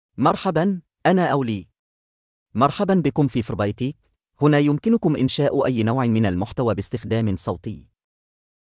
MaleArabic (Bahrain)
AliMale Arabic AI voice
Ali is a male AI voice for Arabic (Bahrain).
Voice sample
Listen to Ali's male Arabic voice.
Male
Ali delivers clear pronunciation with authentic Bahrain Arabic intonation, making your content sound professionally produced.